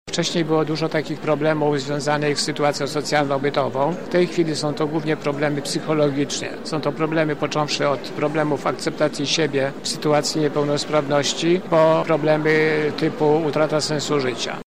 Okazją była Gala „Aktywni z Lublina”.